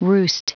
Prononciation du mot roost en anglais (fichier audio)
Prononciation du mot : roost